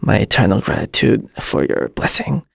Lucias-Vox_Casting3.wav